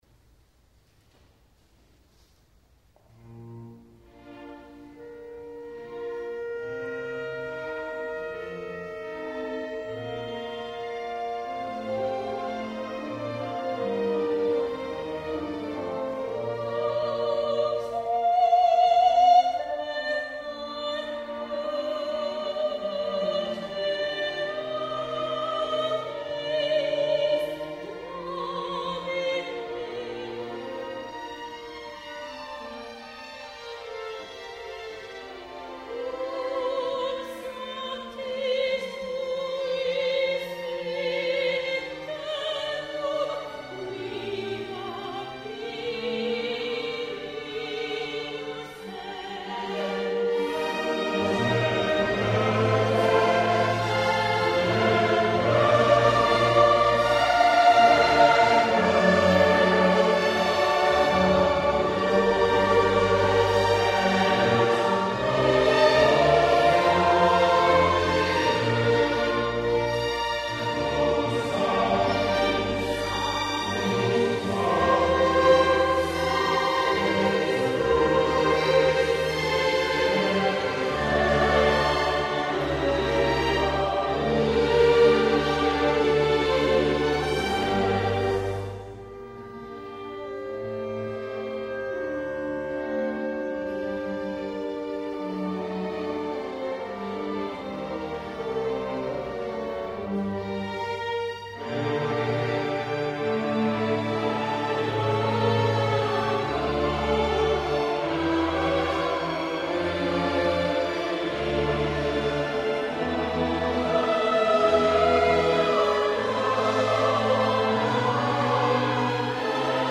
requiem14 communio adagio.mp3